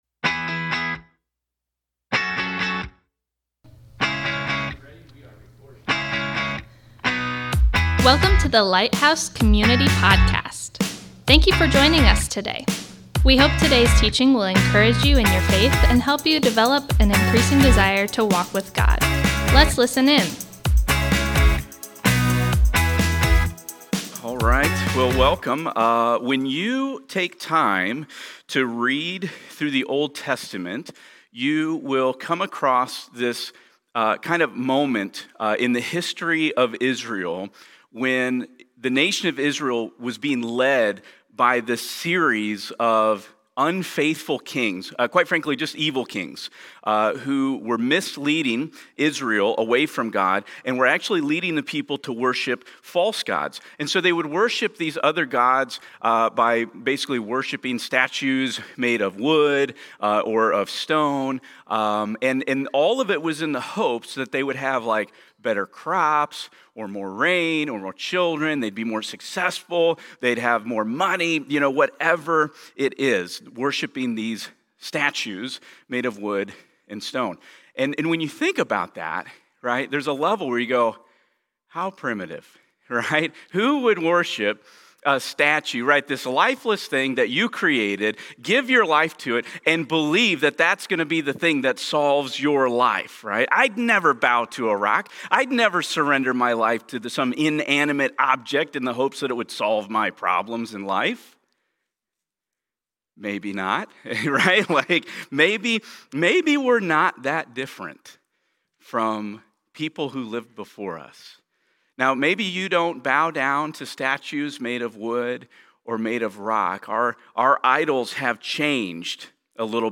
Thank you for joining us today as we come together to worship!We’re kicking off a brand-new teaching series called The Best Part of My Day. Over the next few weeks, we’ll be looking at how to develop a meaningful daily devotional time with God. Today, we’ll be answering the question what does it mean to surrender my life to God?